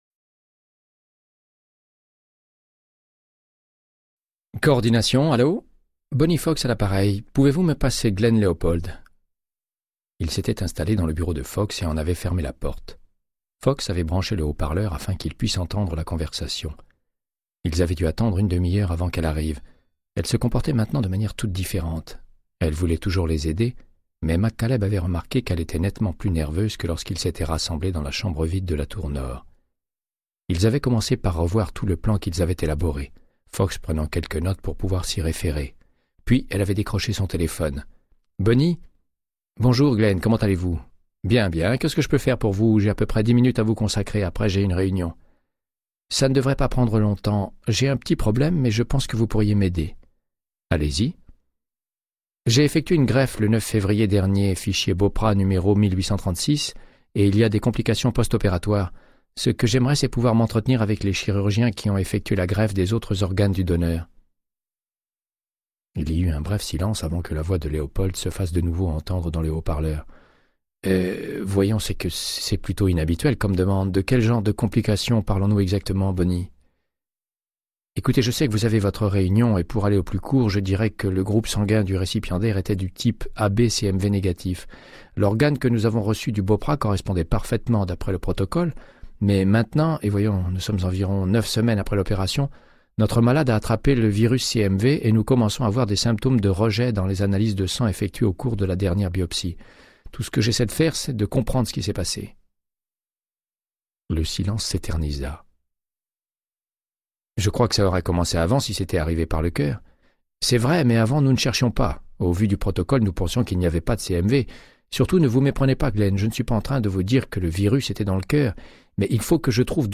Audiobook = Créance de sang, de Michael Connelly - 120